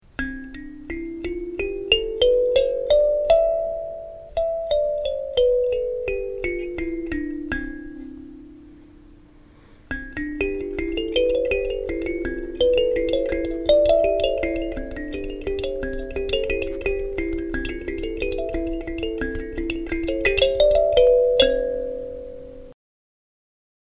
Kalimba
Die Kalimba wird mit beiden Händen gespielt, wobei man die Fingernägel für die Klangerzeugung und die Fingerkuppe zum Abdämmen verwendet.
• Material: Holz, Metall